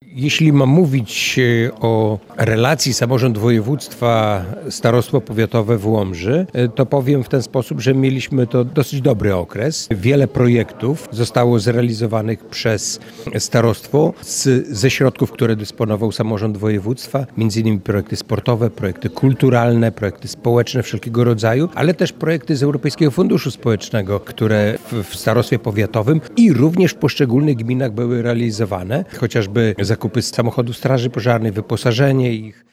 W Starostwie Powiatowym w Łomży odbyło się w poniedziałek (22.12) spotkanie wigilijne.
Członek zarządu województwa podlaskiego zwracał uwagę na owocną współpracę między samorządem województwa podlaskiego, a Starostwem Powiatowym:
Jacek-Piorunek.wav